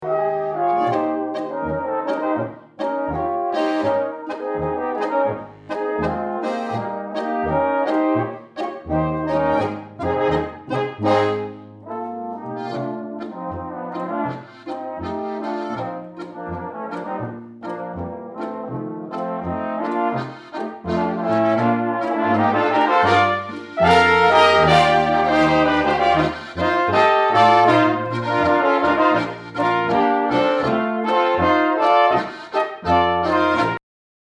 Walzer
Flügelhorn in B
Basstrompete 1 in B
Basstrompete 2 in B
Tuba und Begleitung in C